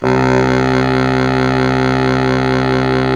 Index of /90_sSampleCDs/Roland L-CDX-03 Disk 1/SAX_Sax Ensemble/SAX_Solo Sax Ens
SAX BASS S13.wav